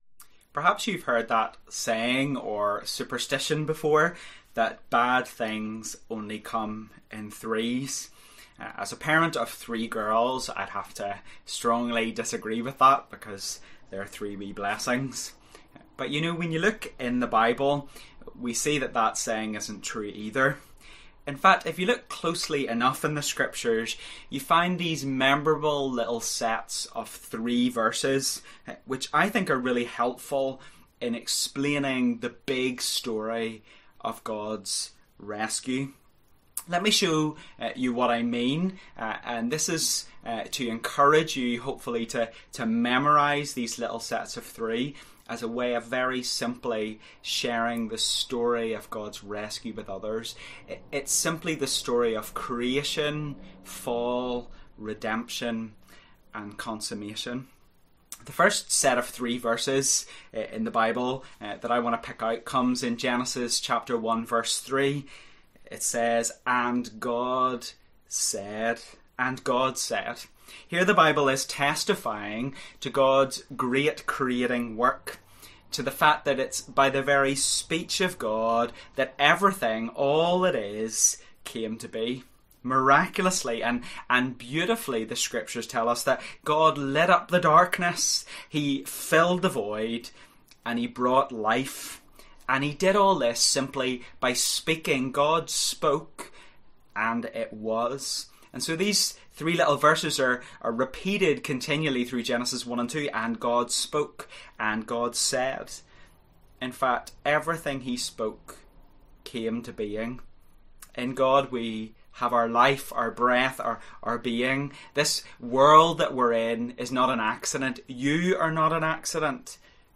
A 3-week sermon series in Hebrews 1- looking at Jesus our Prophet, Priest and King. We will explore what these particular roles Jesus fulfils mean for our salvation, our calling, and our world.